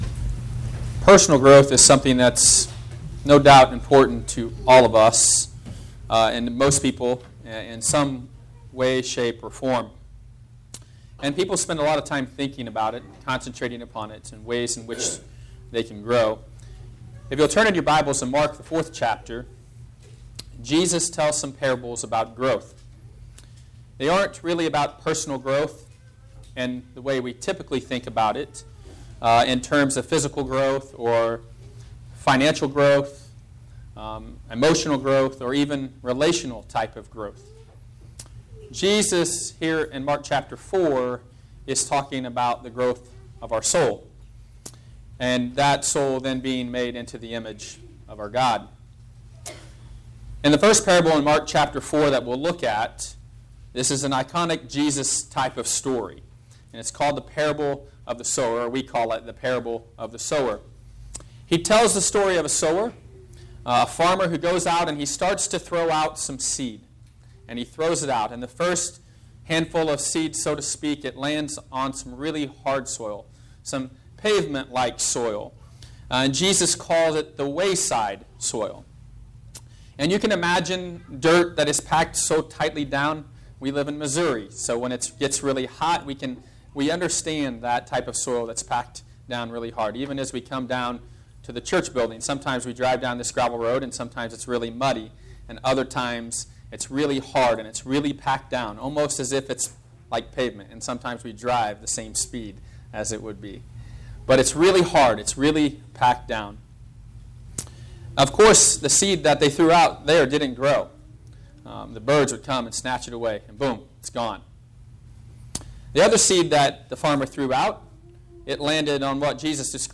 Sunday morning my brother-in-law preached a wonderful sermon.